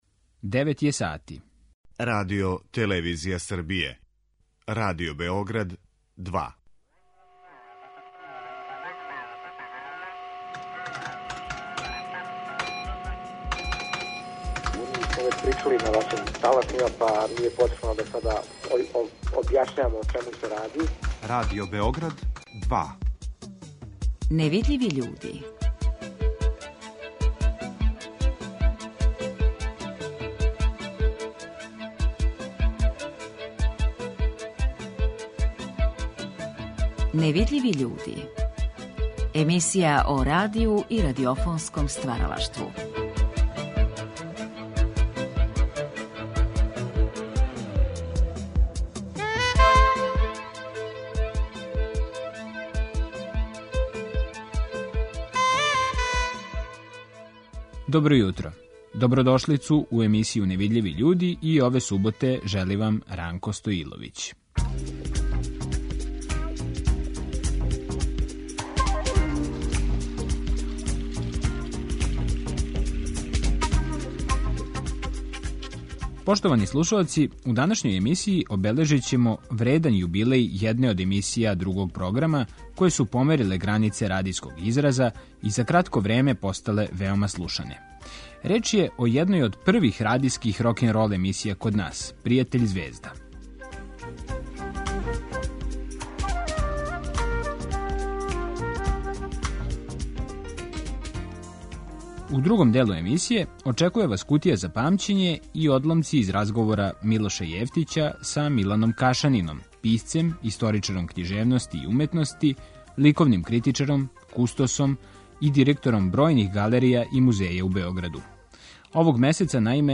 Емисија о радију и радиофонском стваралаштву.